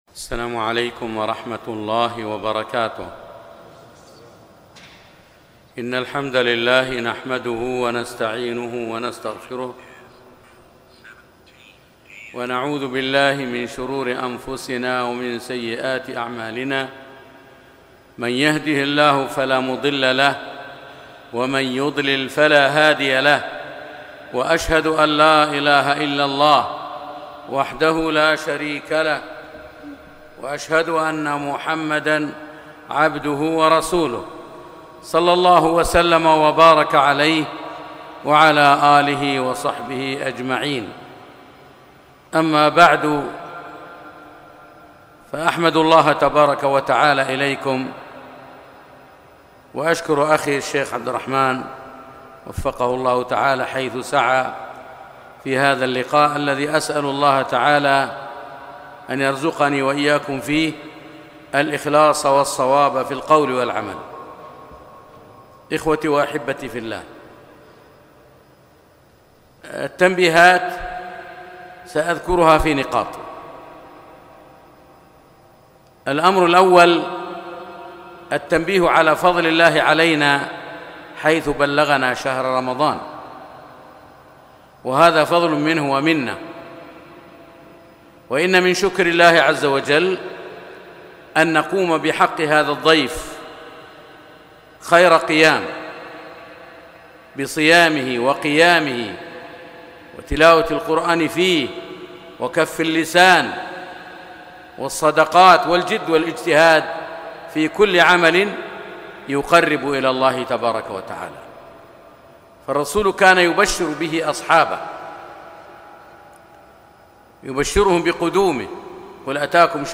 محاضرة - تنبيهات هامة في شهر رمضان